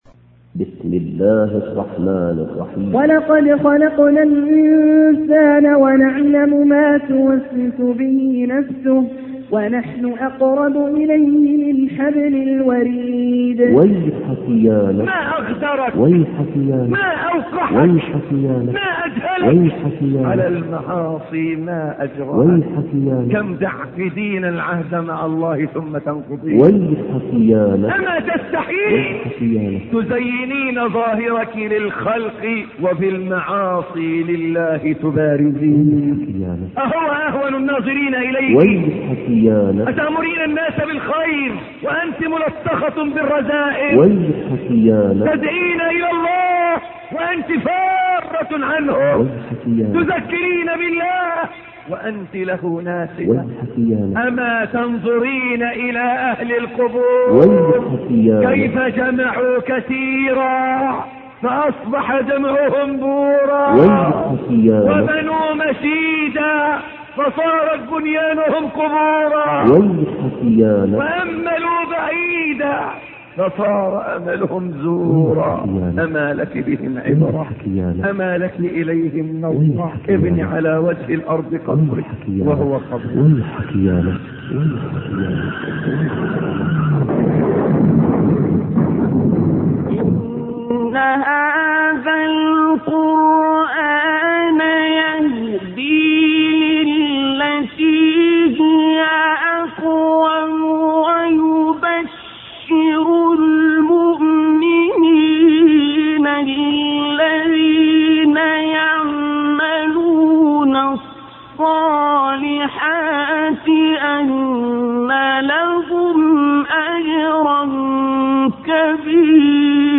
درس
صلاح القلوب للشيخ محمد حسين يعقوب